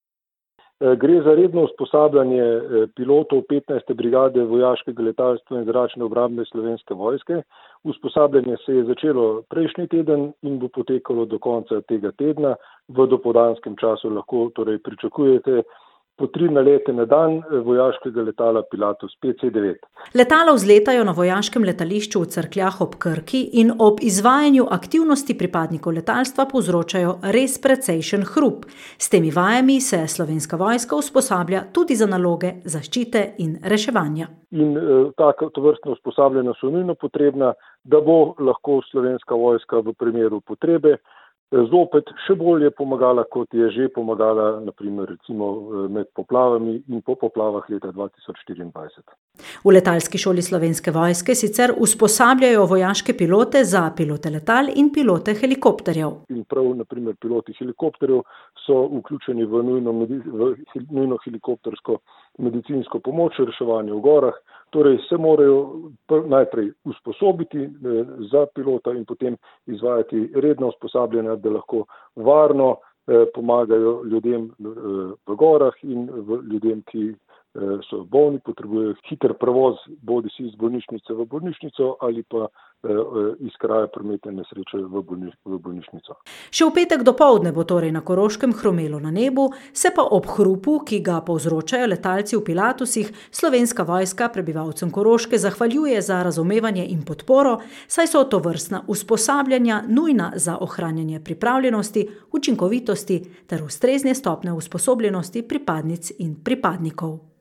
Lokalne novice | Koroški radio - ritem Koroške